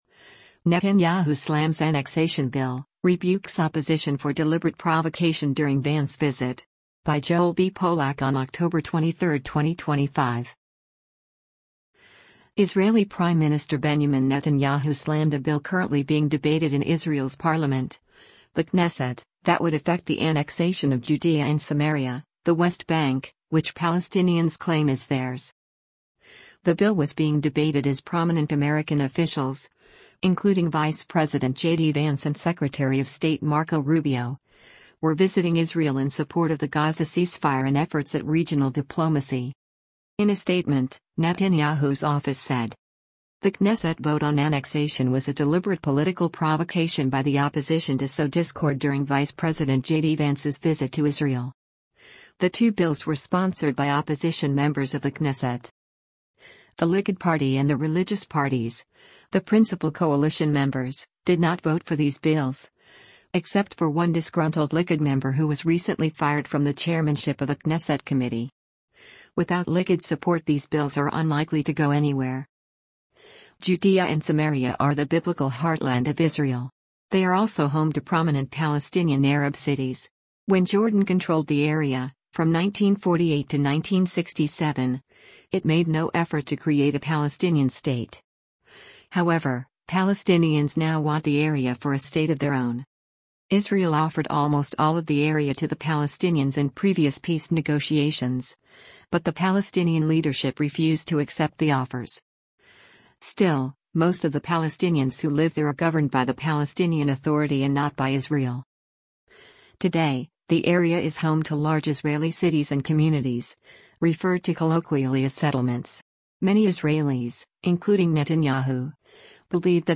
Netanyahu end of war speech (Screenshot / Youtube)